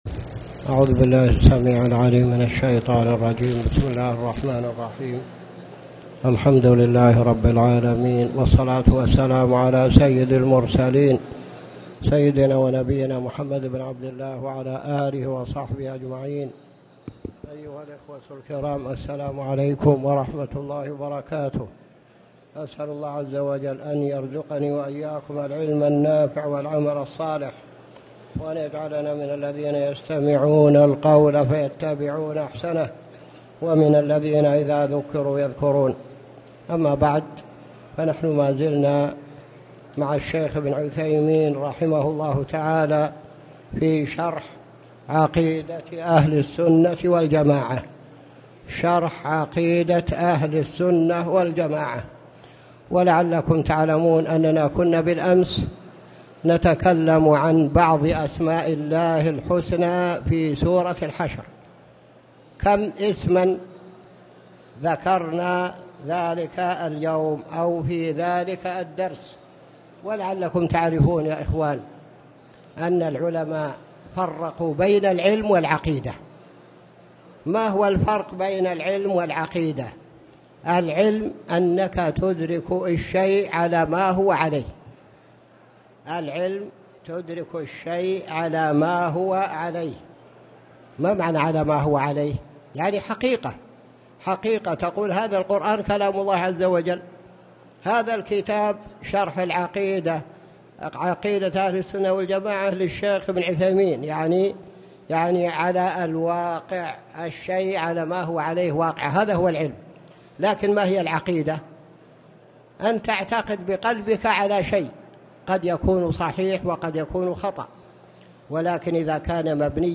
تاريخ النشر ١٨ شعبان ١٤٣٨ هـ المكان: المسجد الحرام الشيخ